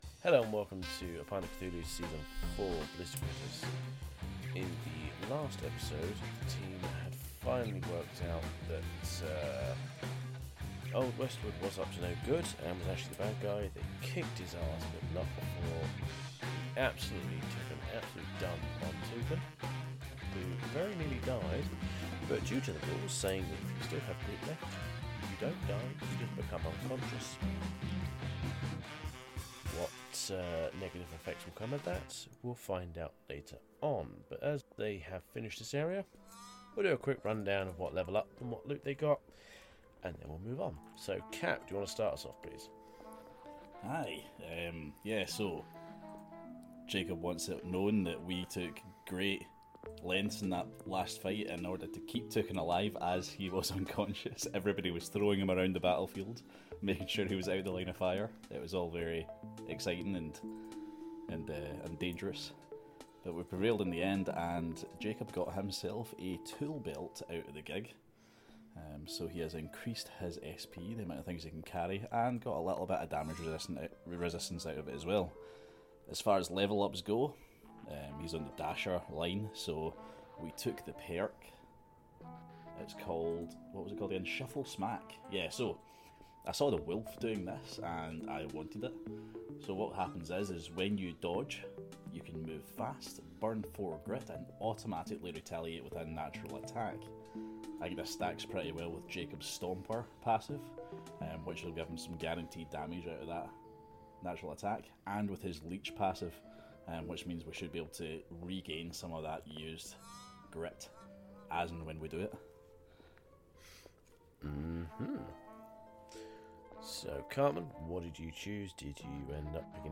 Interview with Parable Games for Sisterhood – A Pint Of Cthulhu – Podcast